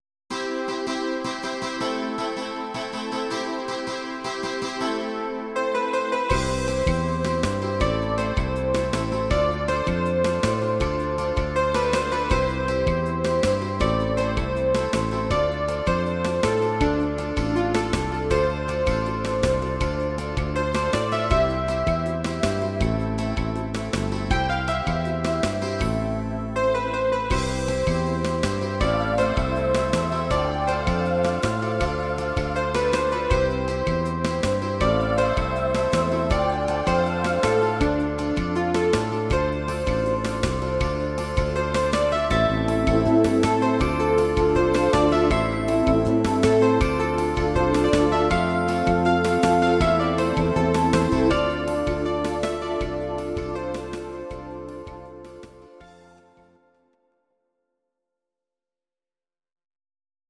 Synthesizer Version